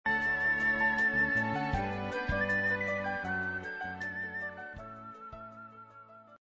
↑6秒程度のイントロ。まるでラジオのようです。